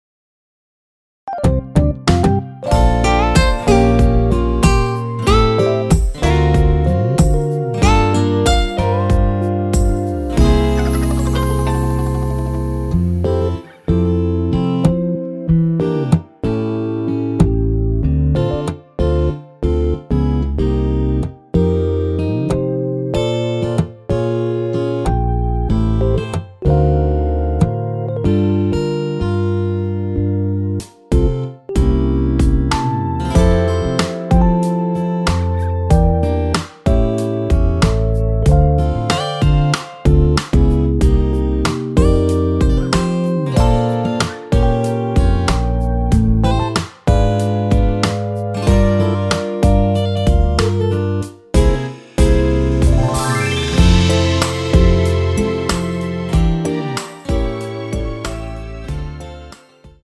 원키에서(+4)올린 MR입니다.
앞부분30초, 뒷부분30초씩 편집해서 올려 드리고 있습니다.
중간에 음이 끈어지고 다시 나오는 이유는